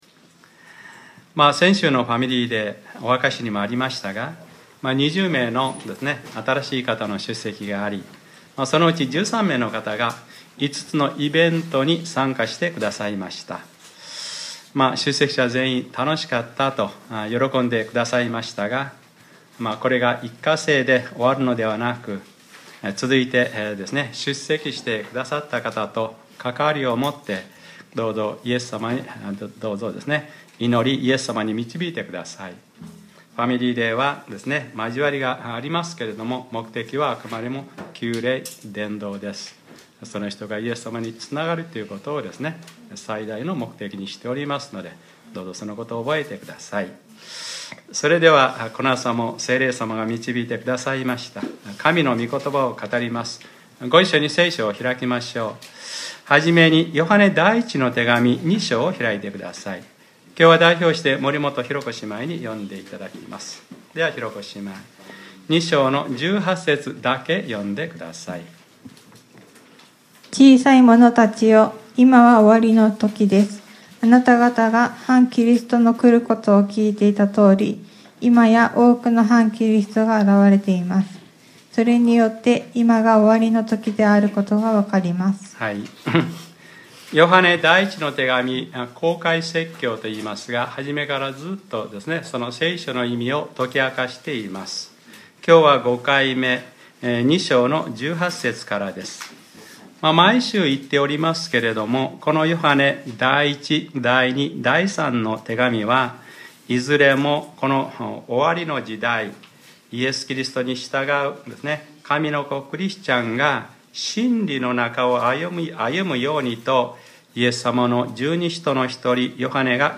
2015年06月28日(日）礼拝説教 『Ⅰヨハネｰ５：終わりの時と反キリスト』